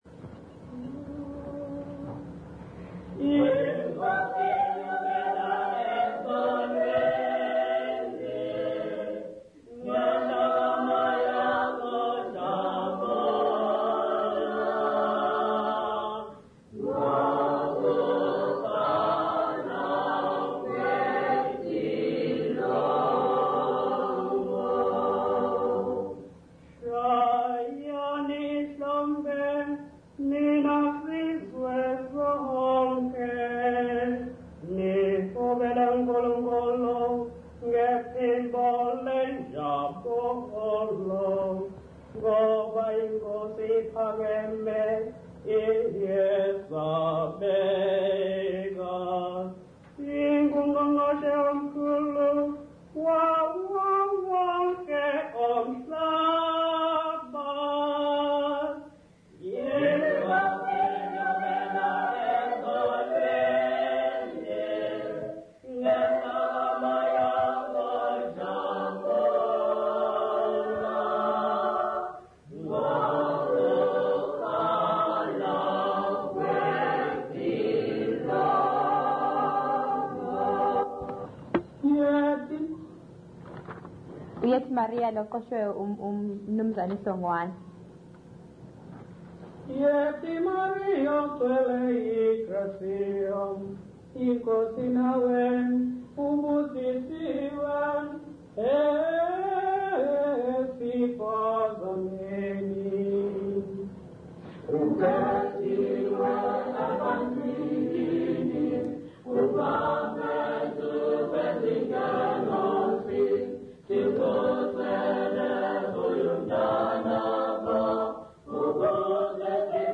Intshanga church music workshop participants
Folk music South Africa
Hymns, Zulu South Africa
field recordings
Unaccompanied church hymn.